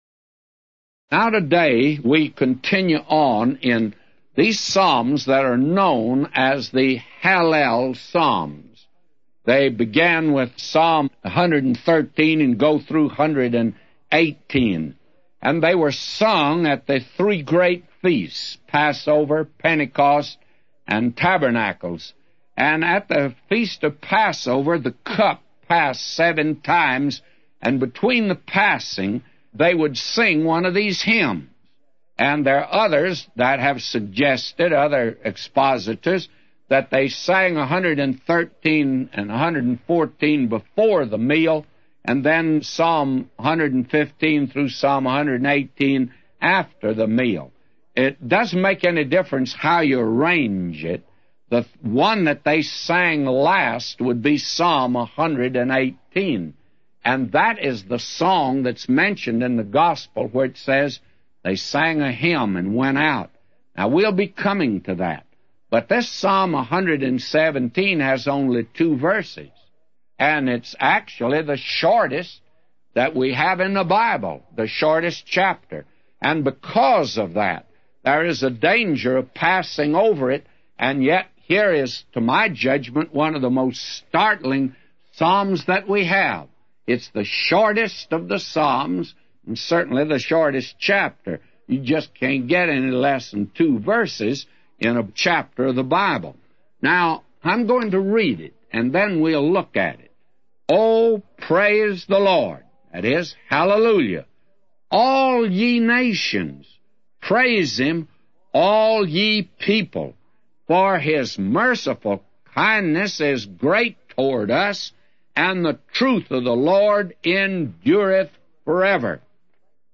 A Commentary By J Vernon MCgee For Psalms 117:1-999